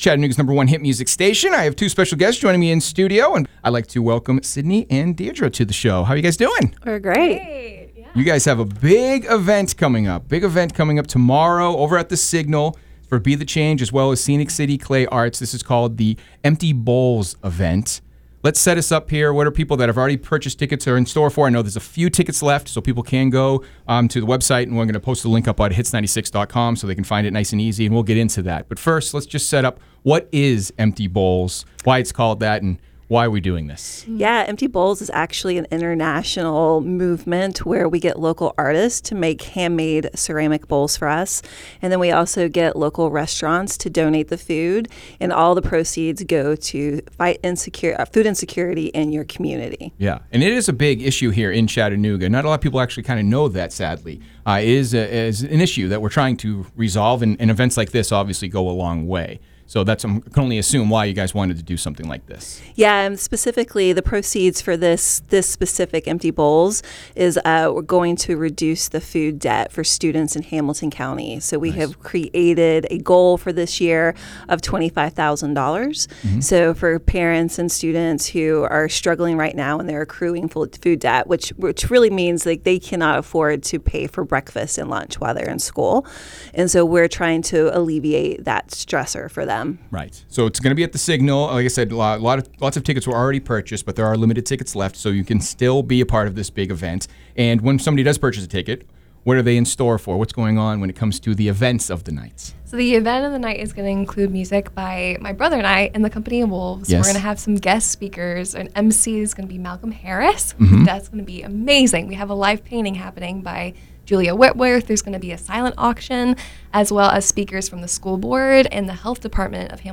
Full-Interview-.wav